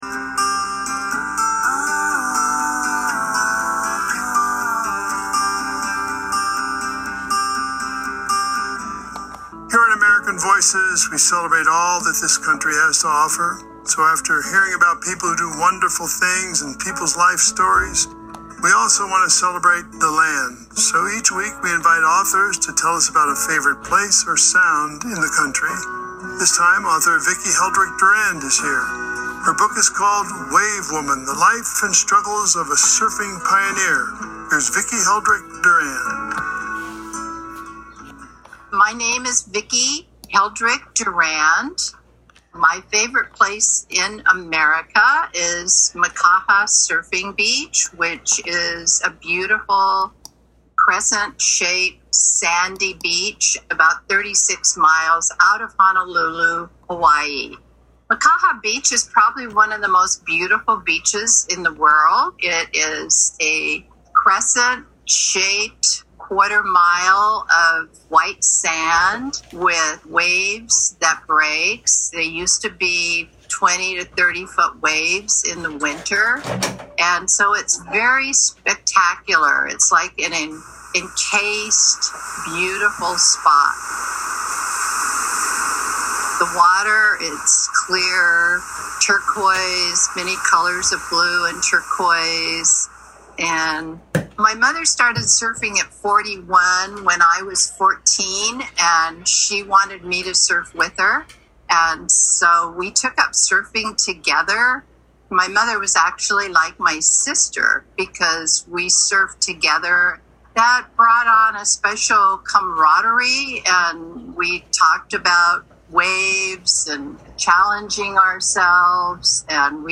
Radio Show: